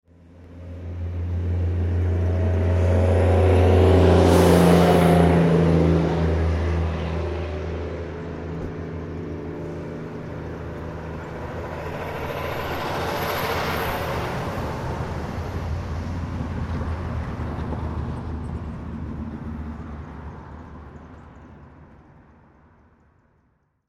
جلوه های صوتی
دانلود صدای اتوبوس 7 از ساعد نیوز با لینک مستقیم و کیفیت بالا